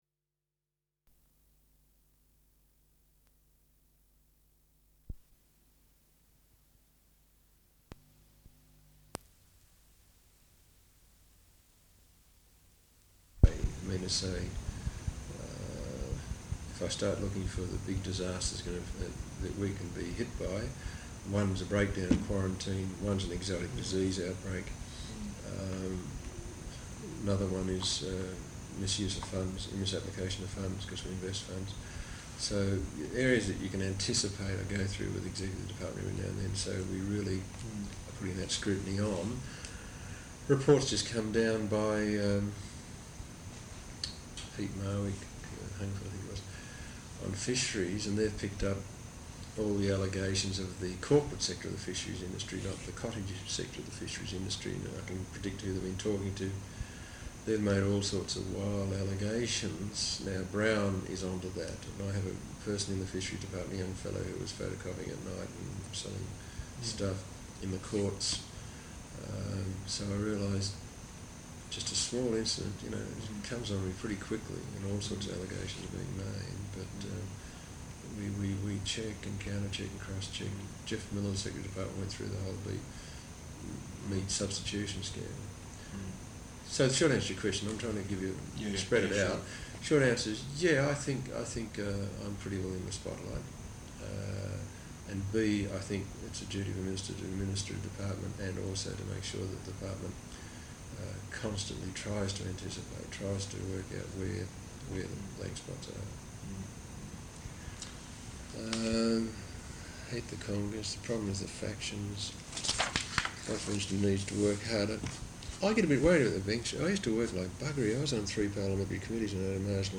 Interview with John Kerin, Minister for Primary Industry, Parliament House, Tuesday 18 April, 1989.